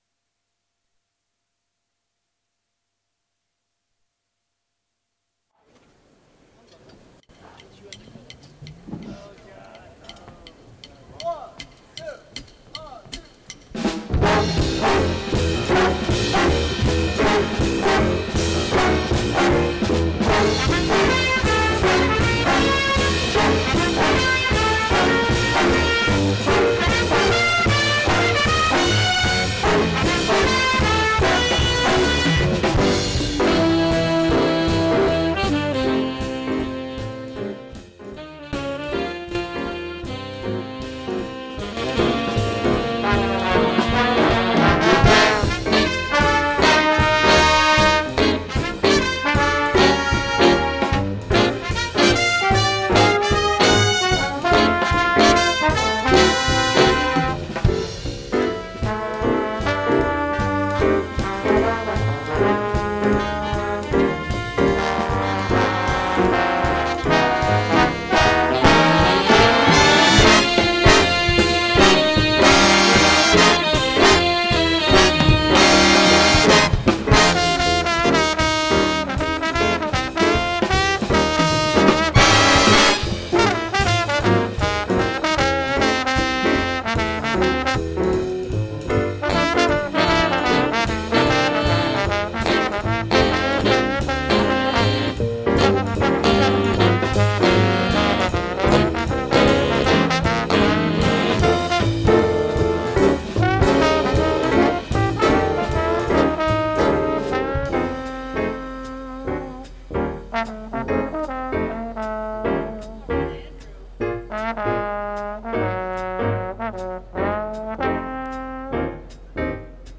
featuring yours truly on trombone solo.